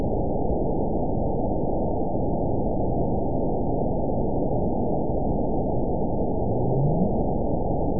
event 922739 date 03/24/25 time 12:04:25 GMT (2 months, 3 weeks ago) score 9.39 location TSS-AB02 detected by nrw target species NRW annotations +NRW Spectrogram: Frequency (kHz) vs. Time (s) audio not available .wav